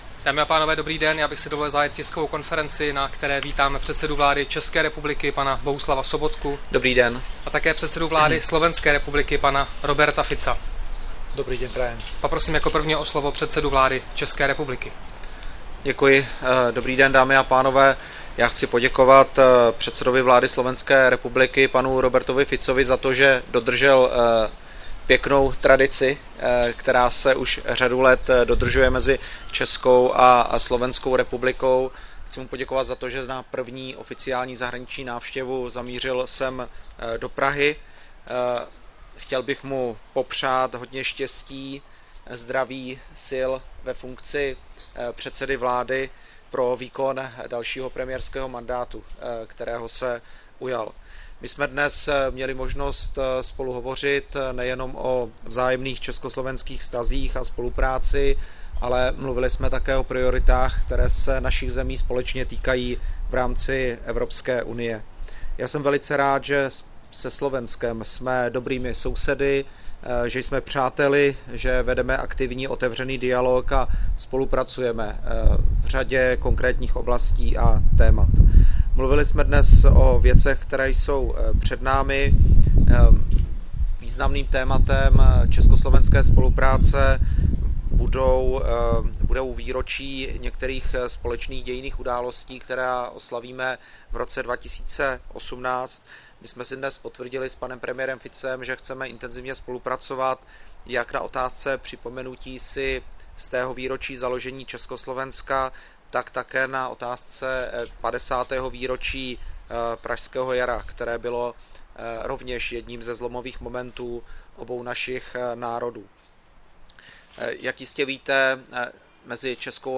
Tisková konference po setkání předsedy vlády ČR Sobotky s předsedou vlády SR Ficem, 8. června 2016